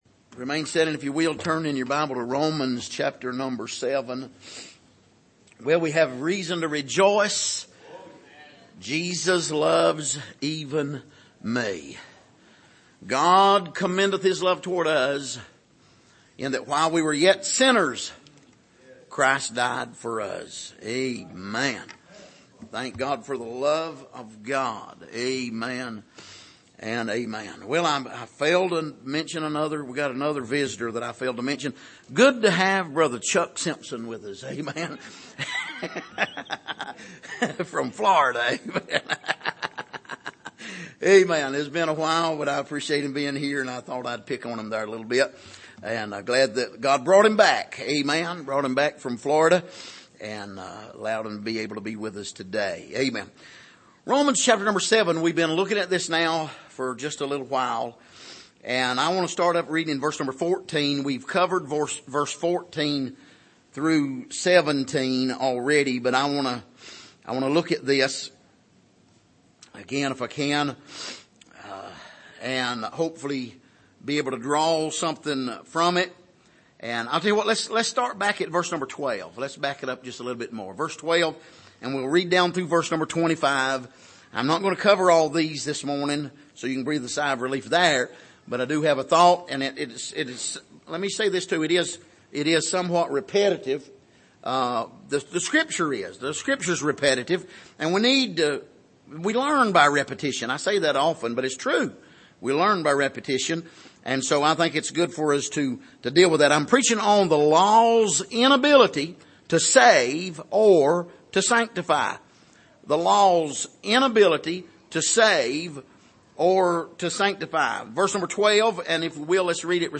Passage: Romans 8:1-4 Service: Sunday Morning